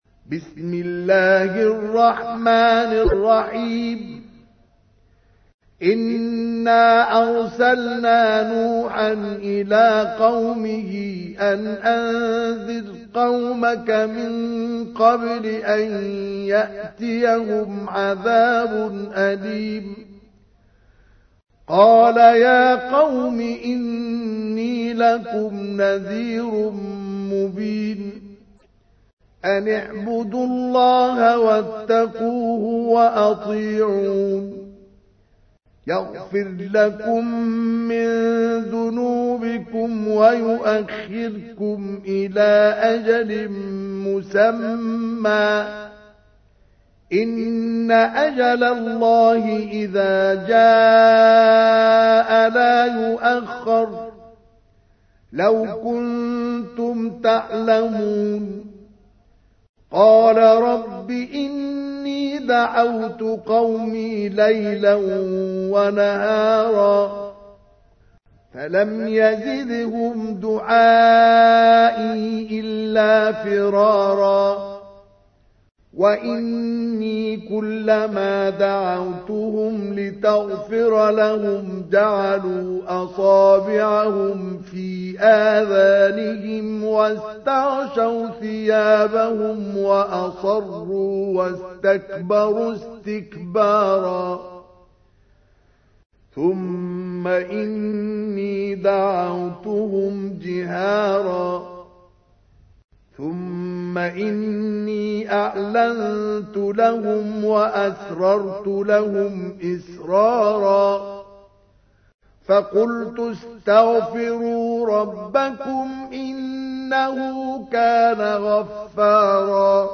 تحميل : 71. سورة نوح / القارئ مصطفى اسماعيل / القرآن الكريم / موقع يا حسين